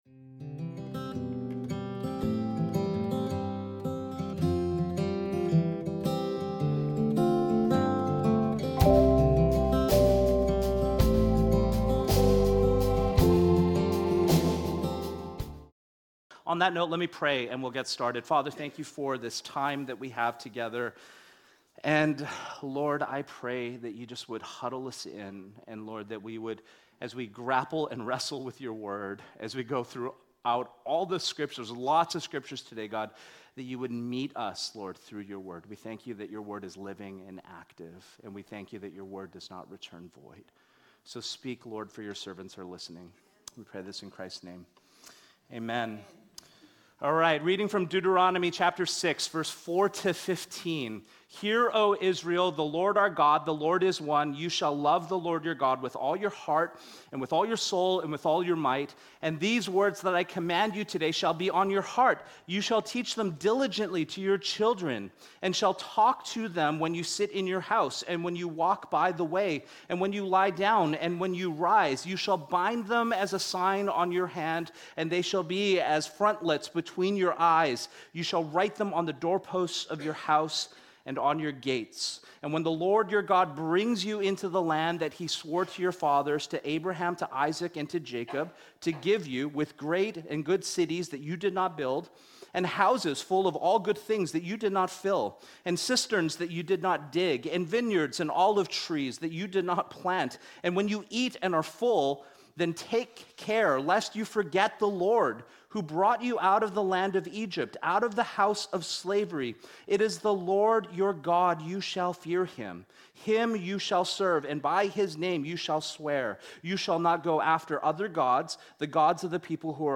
In this message, we conclude our series on Idolatry by looking at our habits, hungers, and practices.